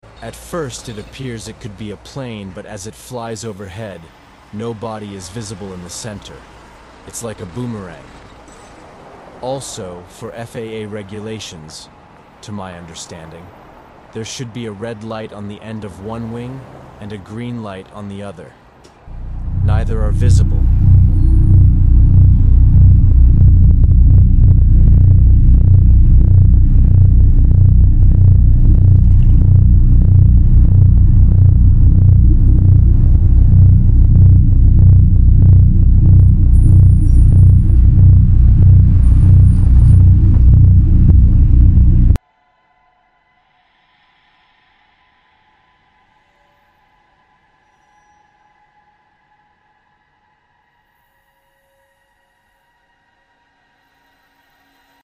Boomerang Shaped Drone Flying Very Low. Sound Effects Free Download
Clear sighting. Neptune, New Jersey.